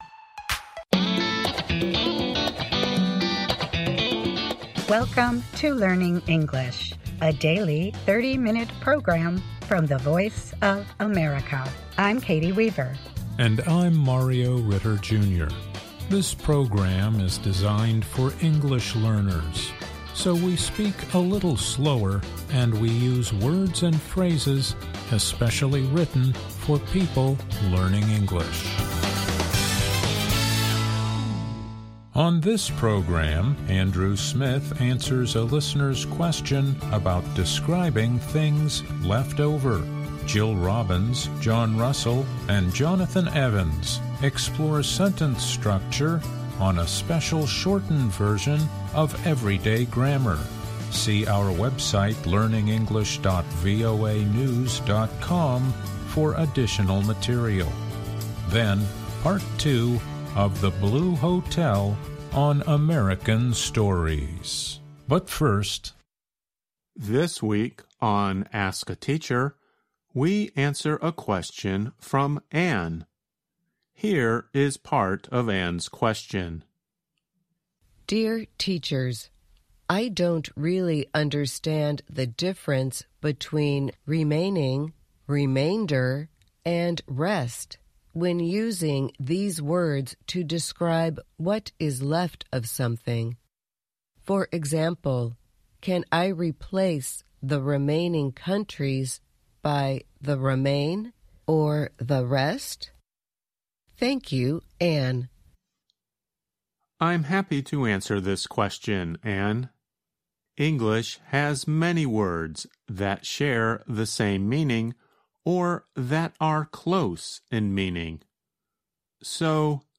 On today’s podcast, some rules about how to talk about left over things on ‘Ask a Teacher;’ how writers use sentence patterns on ‘Everyday Grammar;’ then, part two of a dramatic reading of 'The Blue Hotel,' by Stephen Crane on American Stories.